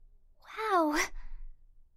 Cute Anime Girl "Wow" Sound Effect
Category 🗣 Voices
Anime Anime-Girl Anime-Girl-Voice Anime-Sound-Effect Anime-Wow Anime-Wow-Sound-Effect Cute Cute-Anime-Girl sound effect free sound royalty free Voices